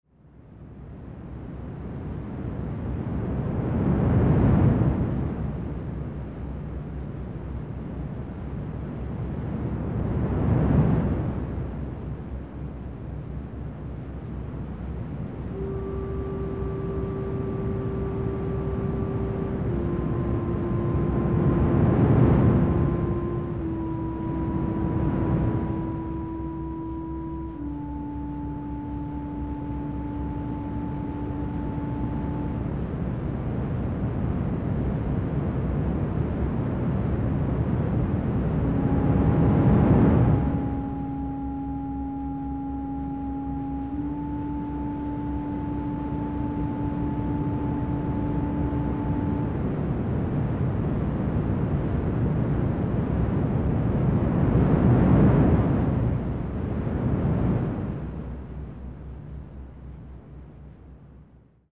Verbální vedení: Neverbální